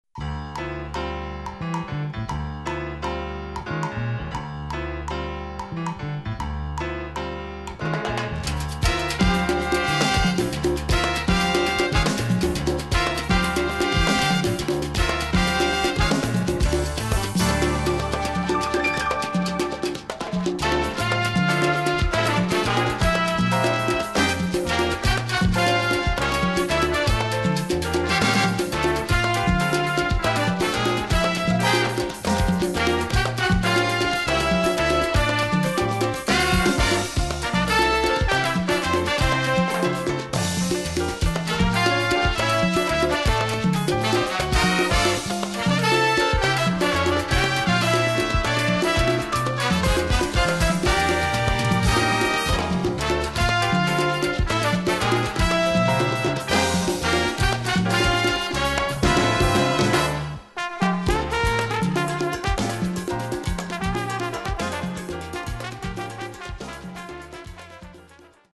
Category: little big band
Style: mambo
Solos: trombone, trumpet, piano, percussion